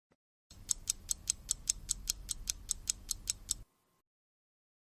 White Noise
White Noise is a free ambient sound effect available for download in MP3 format.
407_white_noise.mp3